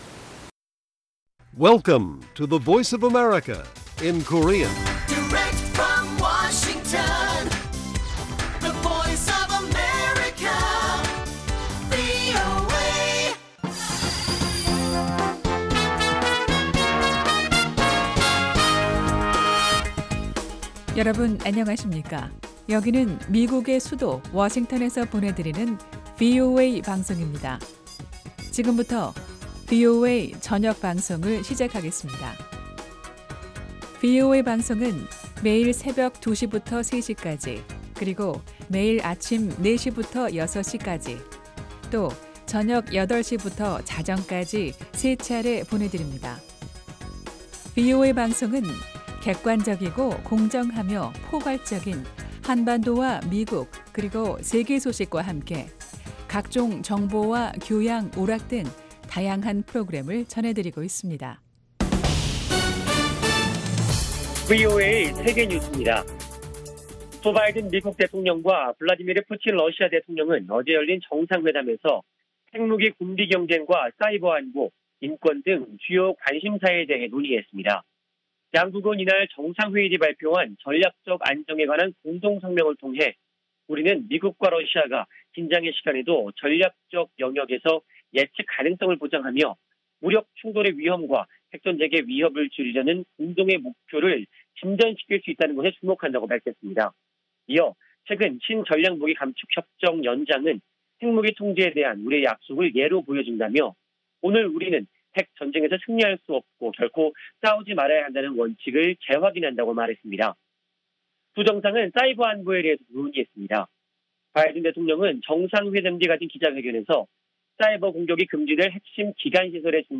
VOA 한국어 간판 뉴스 프로그램 '뉴스 투데이', 2021년 6월 17일 1부 방송입니다. 미 국방부 인도태평양 담당 차관보 지명자는 상원 군사위 인준청문회에 제출한 서면답변에서 미-한-일 3국 협력은 북한에 대한 미국 전략의 중심이 되는 요소라고 밝혔습니다.